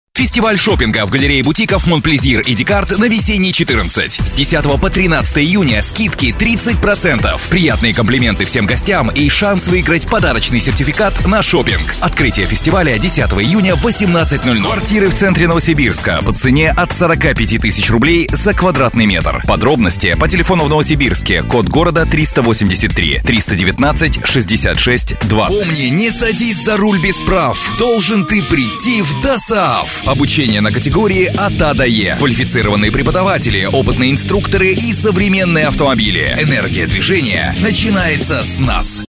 Опыт озвучивания рекламных роликов, работа ведущим на радио - более 20 лет.
Тракт: предусилитель DBX, микрофон Electro-Voice